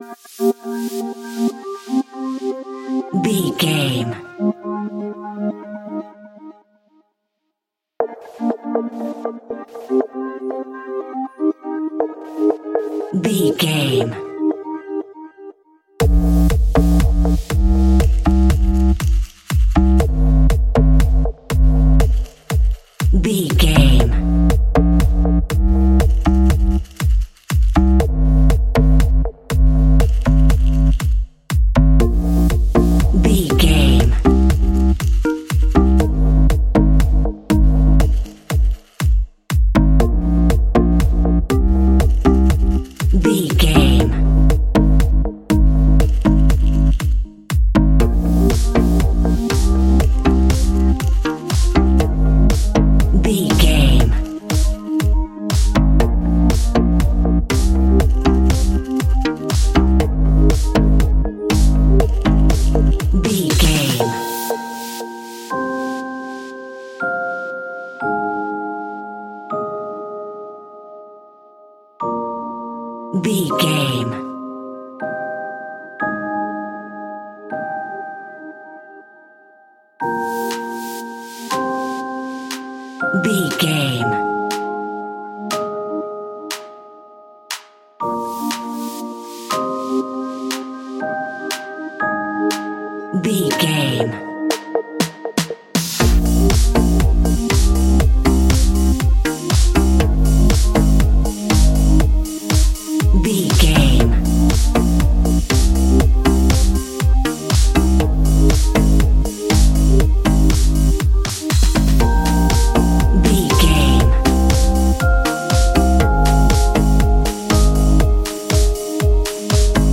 Aeolian/Minor
synthesiser
drum machine
sleigh bells